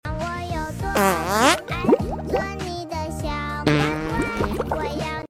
Sound Effects Downloader To Help You Download The Highest Quality Capybara, Das Furzt Capybara Who Sound Effects Free Download For TikTok Videos.